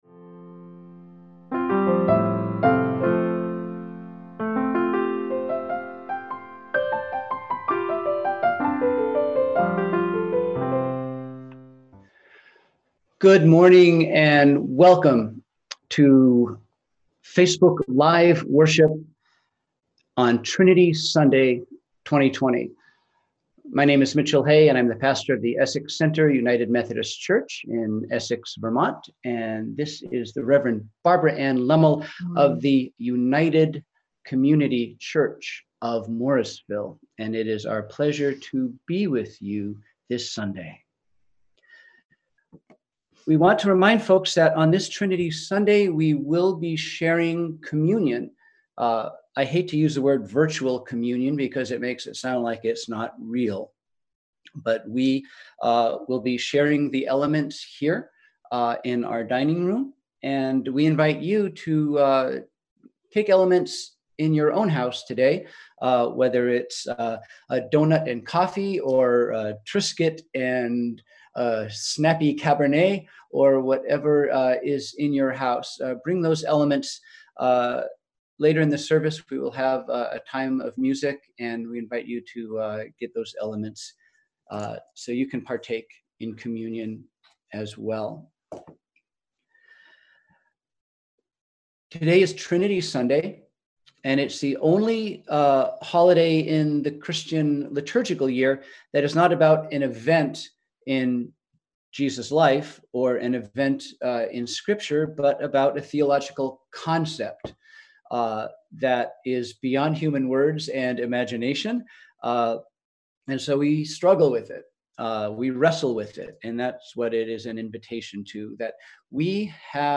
We held virtual worship on Sunday, June 7, 2020 at 10am!